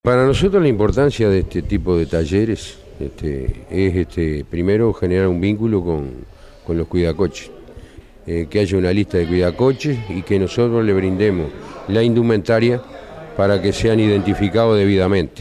alcides_perez_alcalde_de_pando_0.mp3